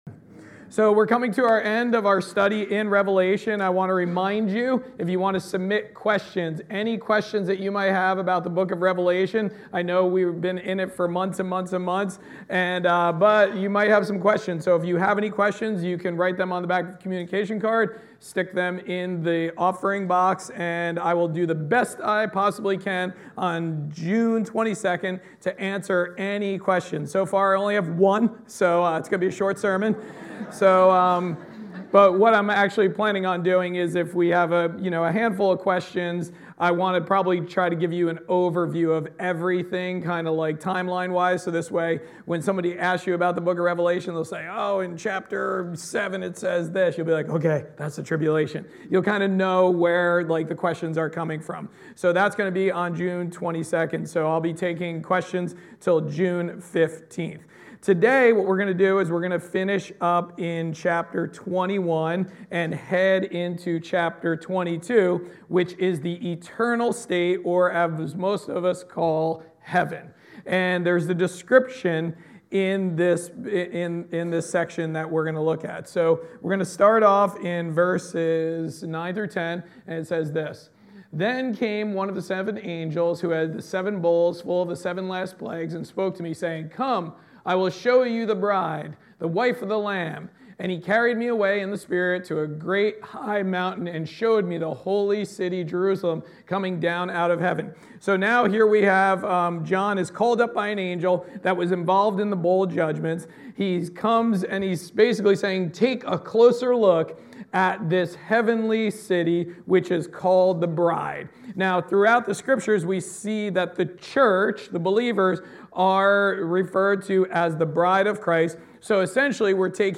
Sermons | Forked River Baptist Church